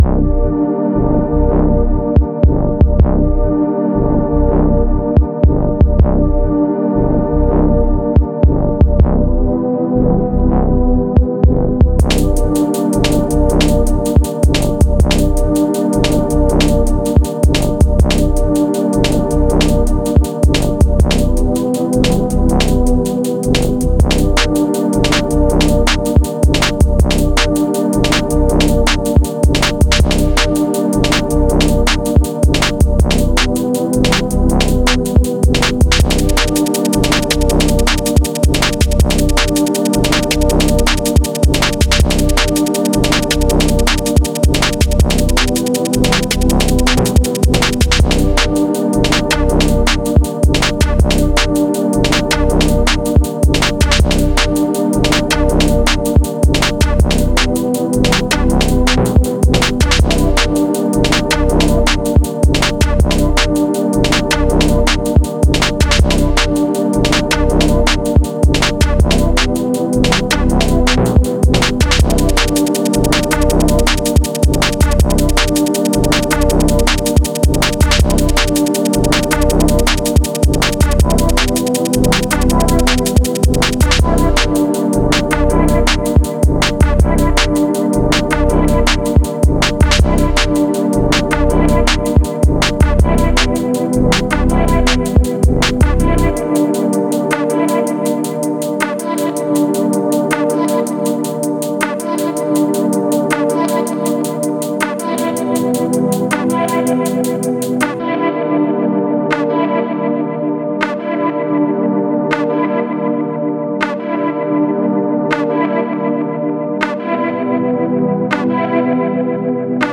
Dt2///ipad master///oxi e16/Belgrade 160bpm